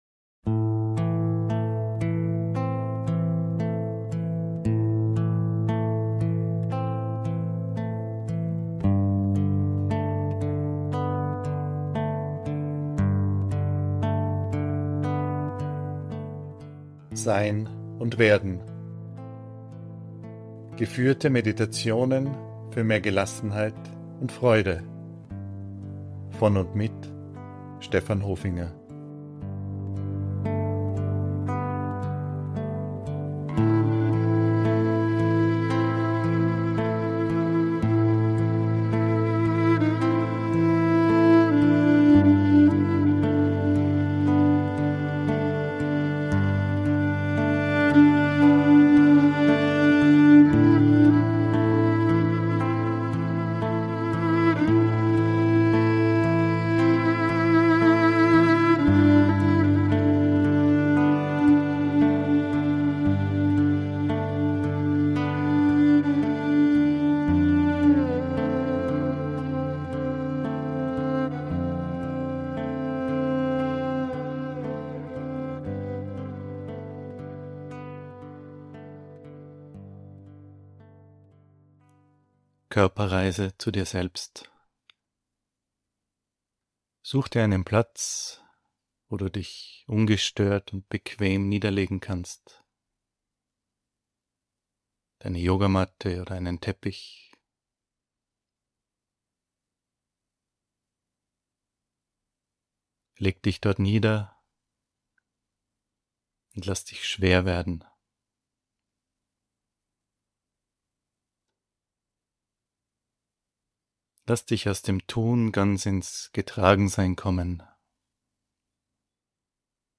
Körperreise – ein Klassiker.